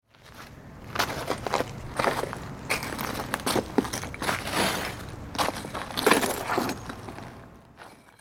rubble_0.ogg